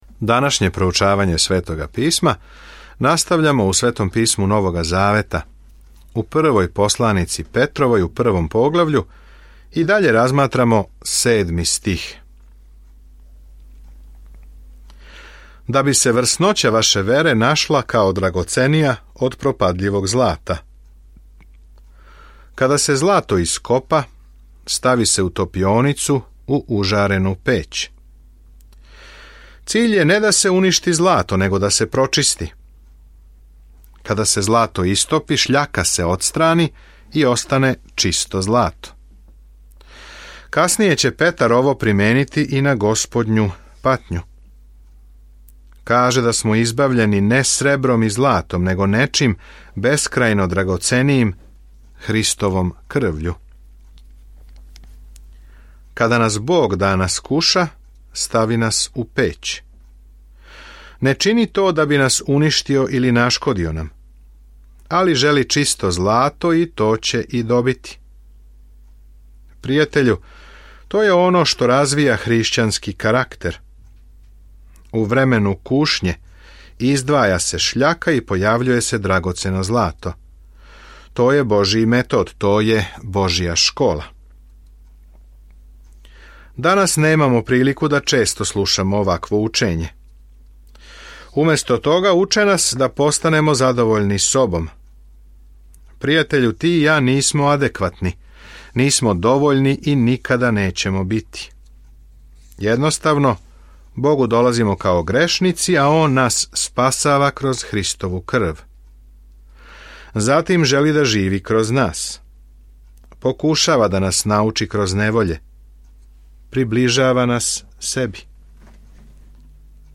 Петрову док слушате аудио студију и читате одабране стихове из Божје речи.